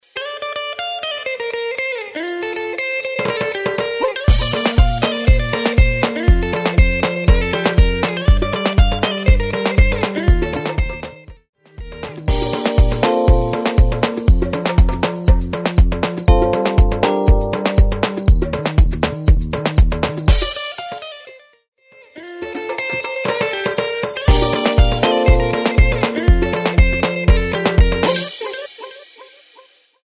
122 BPM
Upbeat World